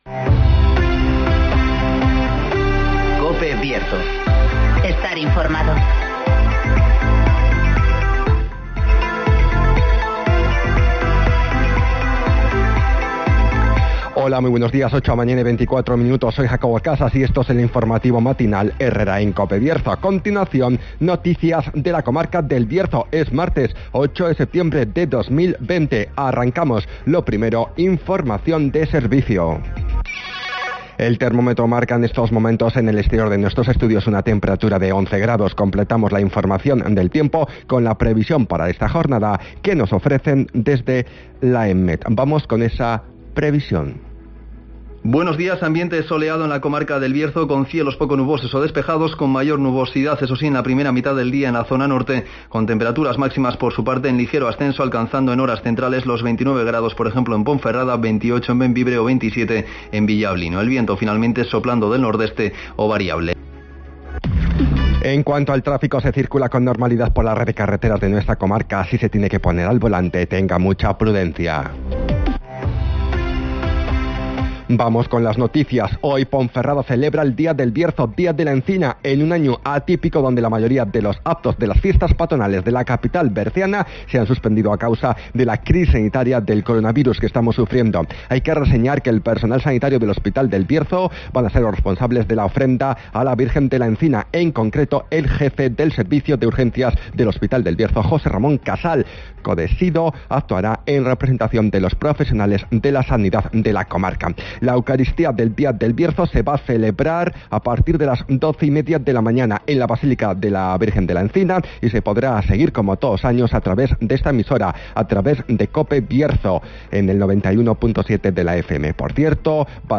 INFORMATIVOS
Conocemos las noticias de las últimas horas de nuestra comarca, con las voces de los protagonistas
-Palabras de Lorena Valle, concejala de Fiestas de Ponferrada
-Declaraciones de Rocío Lucas, consejera de Educación de la Junta de Castilla y León